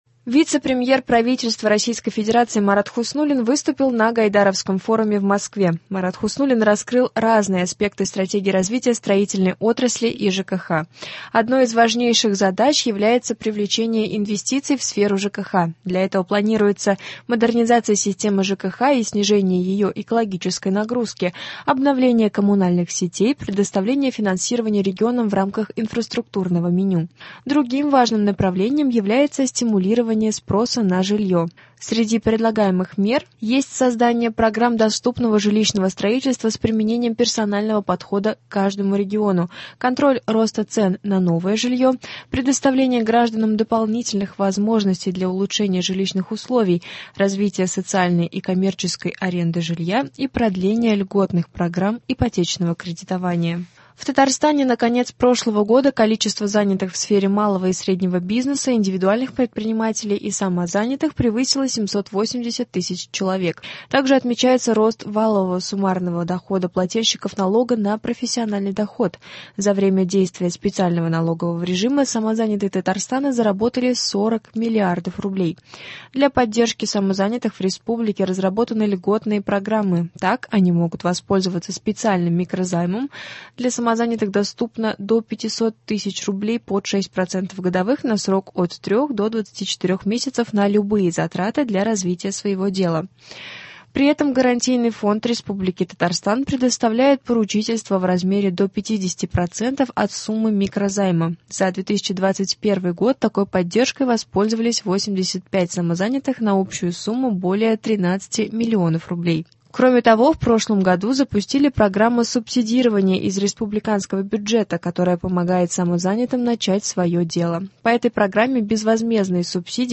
Новости (17.01.22)